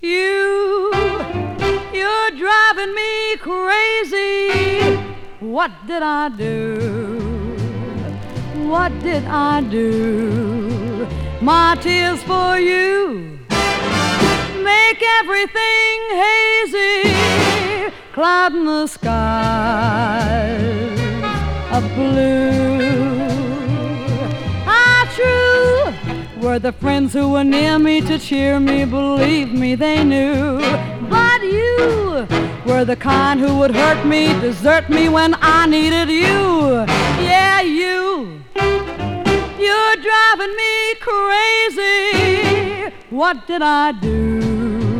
Jazz, Pop, Vocal, Easy Listening　USA　12inchレコード　33rpm　Stereo